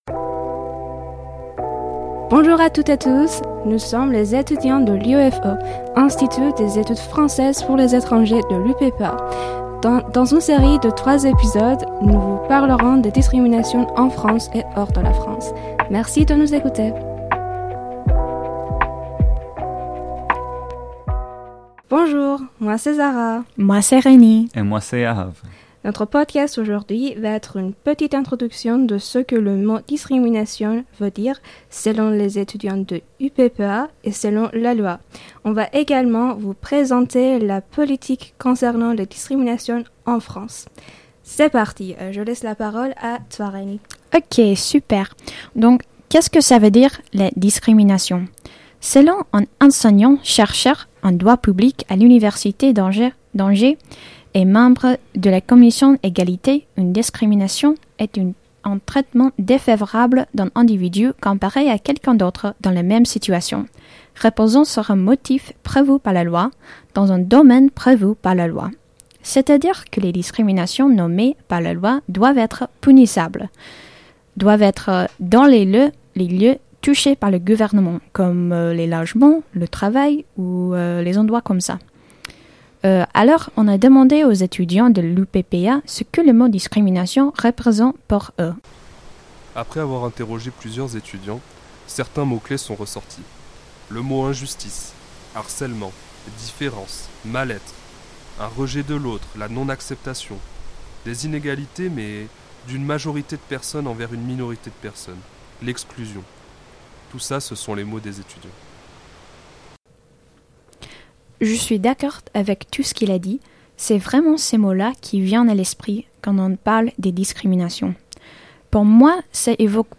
Micro-Trottoir sur les discriminations